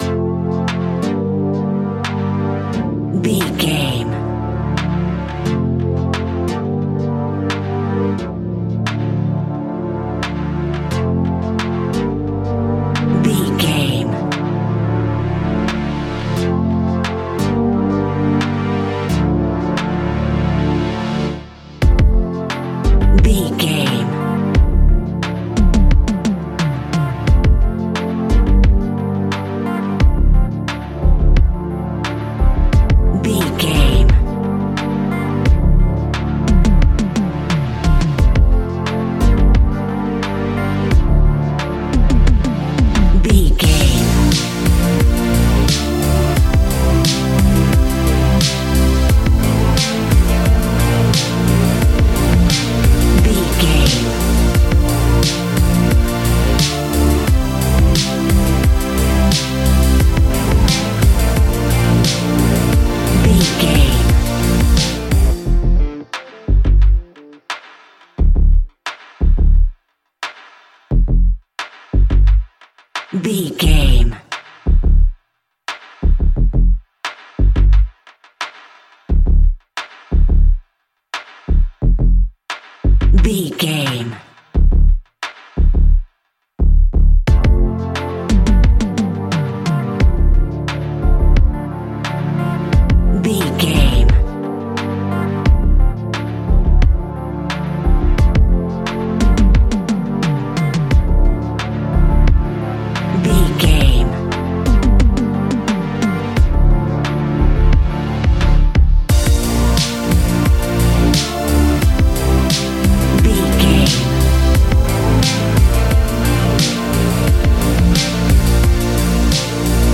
Aeolian/Minor
D
groovy
uplifting
futuristic
driving
energetic
cheerful/happy
repetitive
synthesiser
drum machine
electronic
synth leads
synth bass